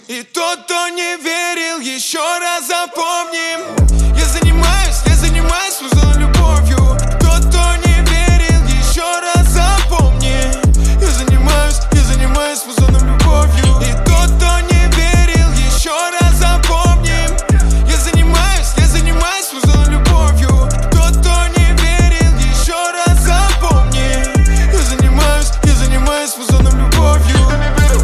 • Качество: 128, Stereo
Хип-хоп